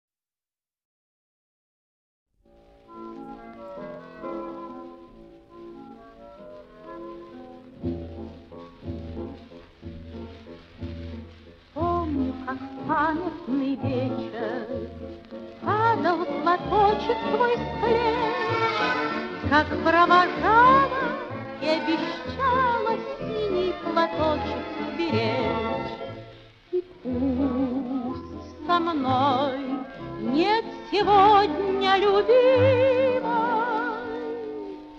Pop in Russian Pop Russian Chanson in Russian